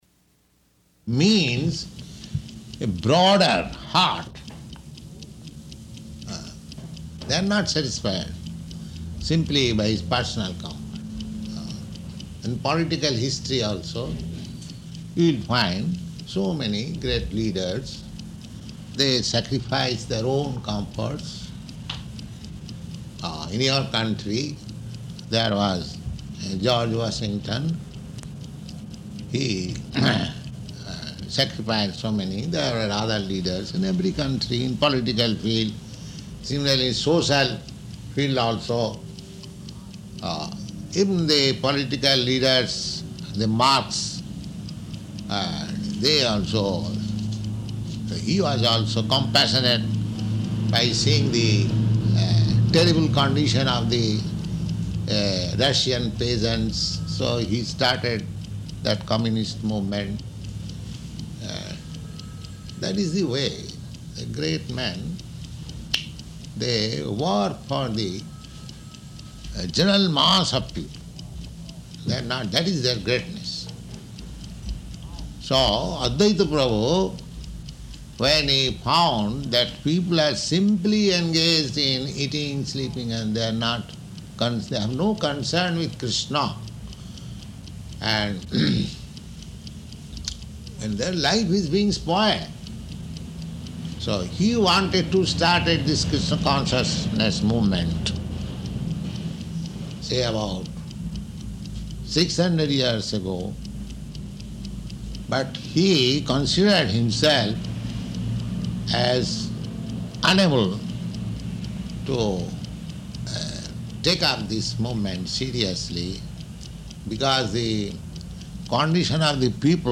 His Divine Grace Śrīla Bhaktisiddhānta Sarasvatī Gosvāmī Prabhupāda's Appearance Day Lecture
Location: Los Angeles